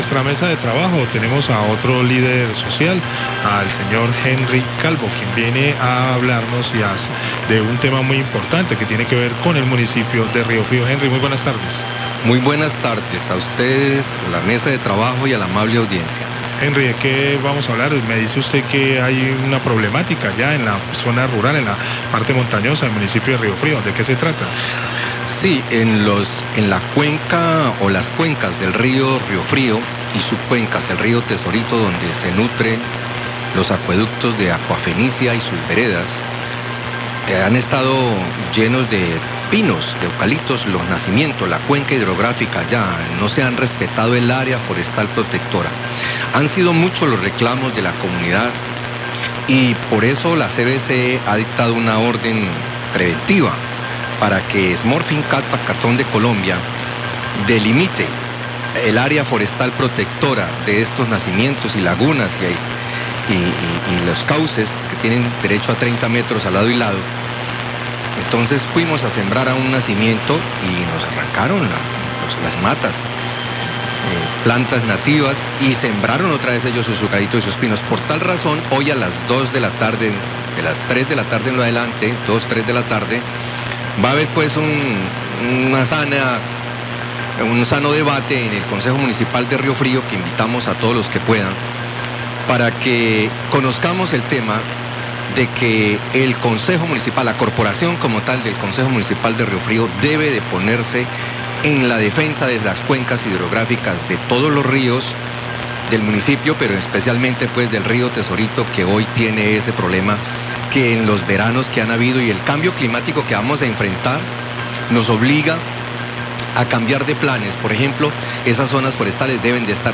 Radio
Líder de comunidad de Río Frío habló al aire que la empresa Cartón Colombia no está respetando los nacimientos, la cuenca hidrográfica, a pesar de la orden de la CVC, ésta empresa está sembrando pinos y eucaliptos fuera de lo límites.  Por esto se debate en el concejo municipal de Río Frío la protección.